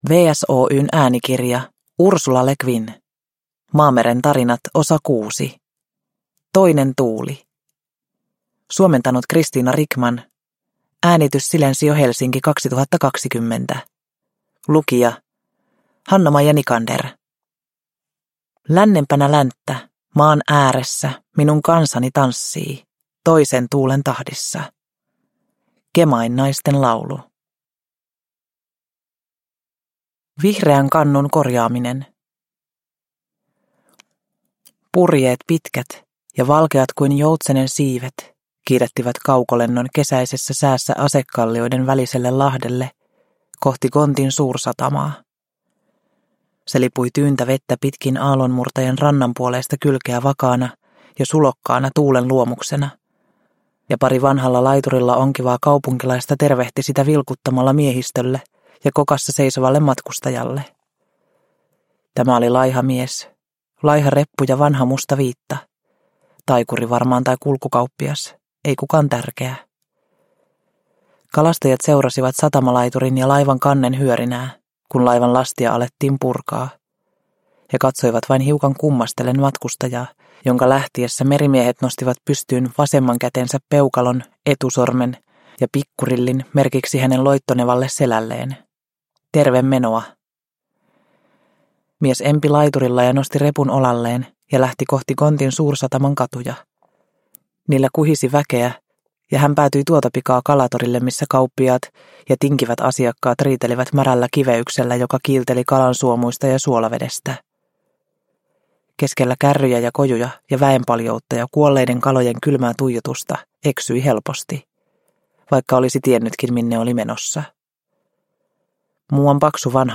Toinen tuuli – Ljudbok – Laddas ner